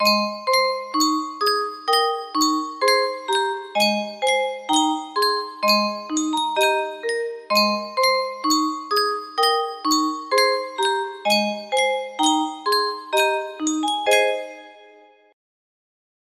Yunsheng Music Box - Beethoven Ode to Joy Y257 music box melody
Full range 60